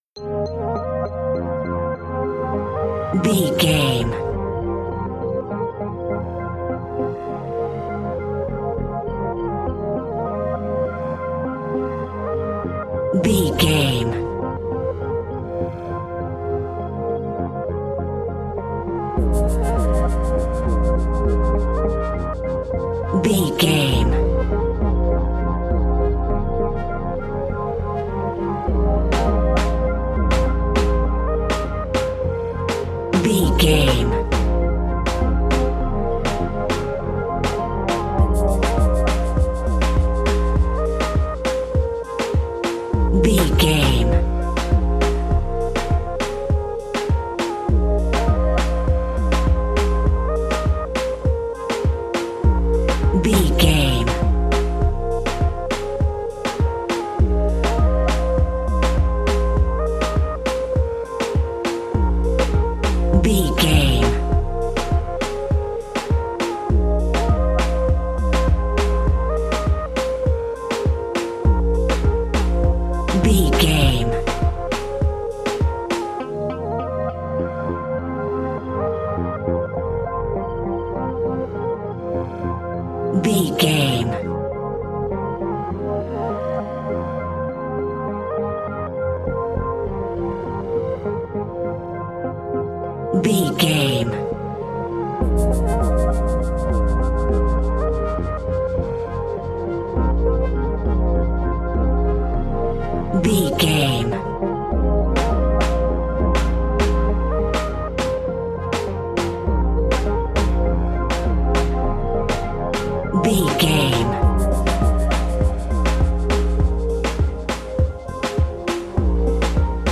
Classic reggae music with that skank bounce reggae feeling.
Ionian/Major
A♭
laid back
chilled
off beat
drums
skank guitar
hammond organ
percussion
horns